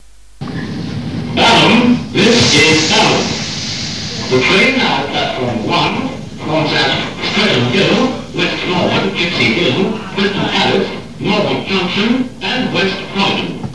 Balham pre-recorded platform announcement number 141 (year:1989)